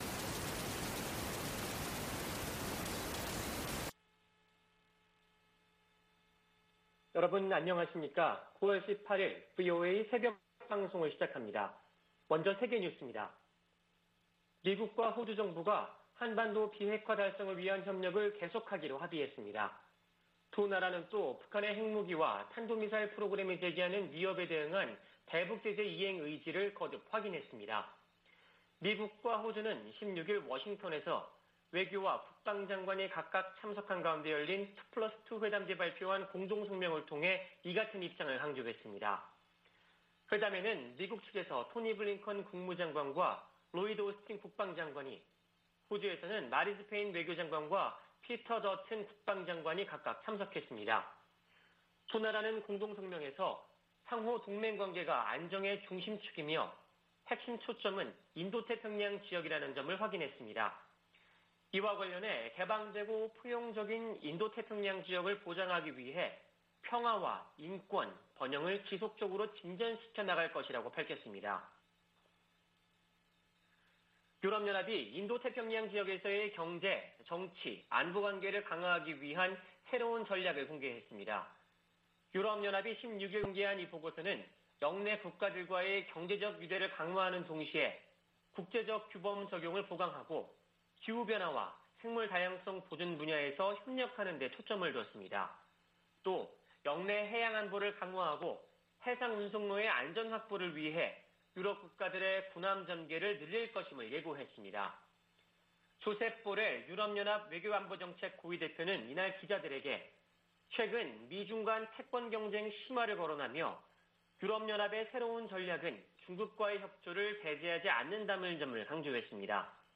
VOA 한국어 '출발 뉴스 쇼', 2021년 9월 18일 방송입니다. 북한이 영변 우라늄 농축 시설을 확장하는 정황이 담긴 위성사진이 공개됐습니다. 76차 유엔총회가 14일 개막된 가운데 조 바이든 미국 대통령 등 주요 정상들이 어떤 대북 메시지를 내놓을지 주목됩니다. 유럽연합(EU)은 올해도 북한 인권 규탄 결의안을 유엔총회 제3위원회에 제출할 것으로 알려졌습니다.